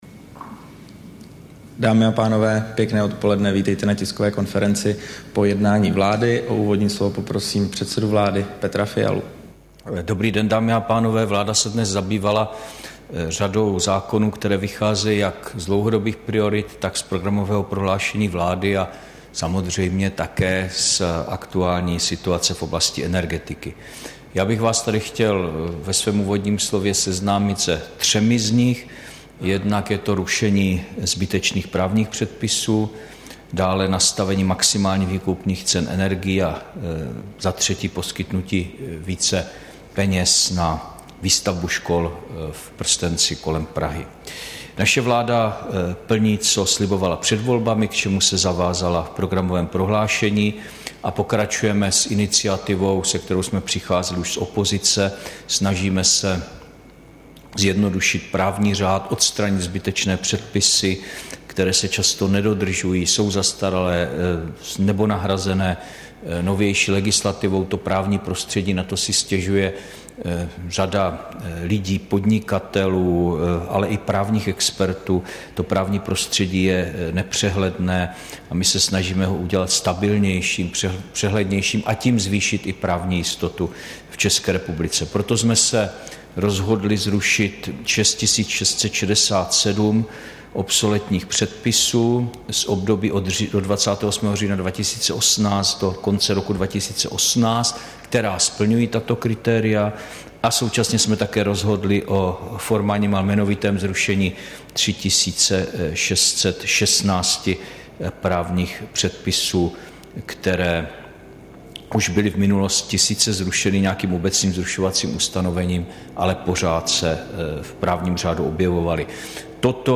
Tisková konference po jednání vlády, 9. listopadu 2022